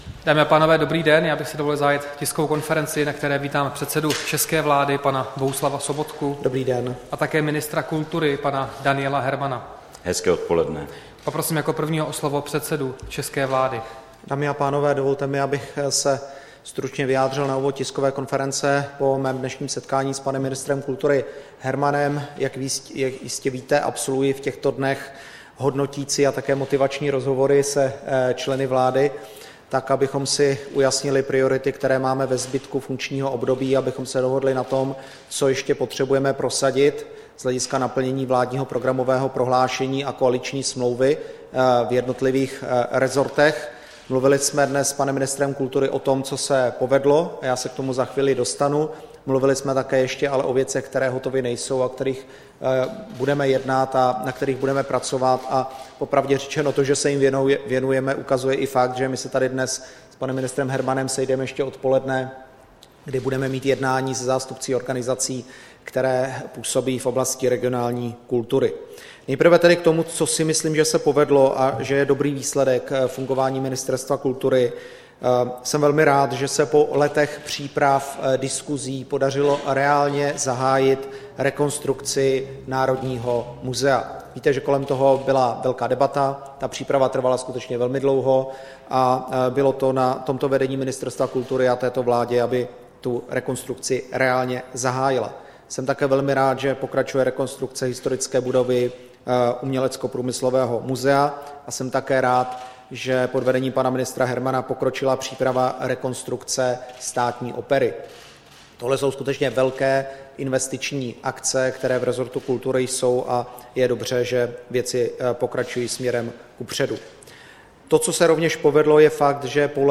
Tisková konference po jednání předsedy vlády Sobotky s ministrem kultury Hermanem, 24. listopadu 2016